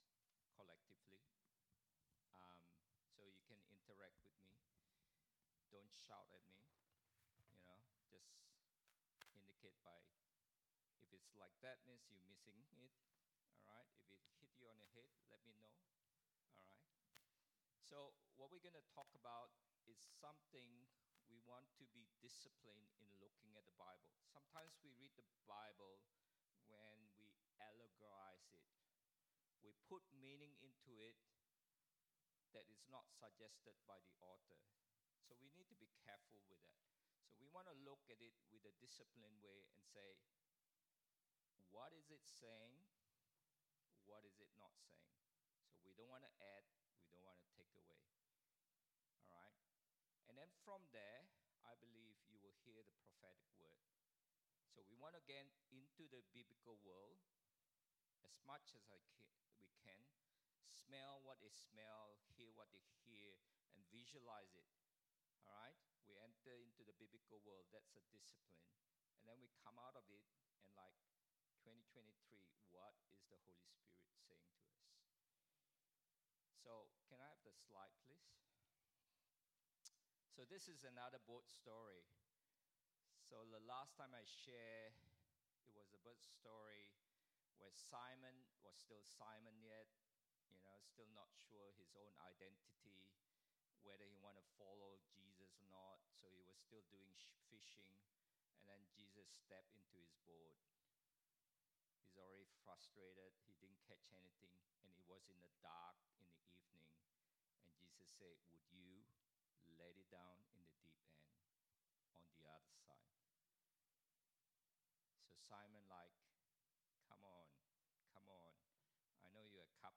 Sermons | Marion Vineyard Christian Fellowship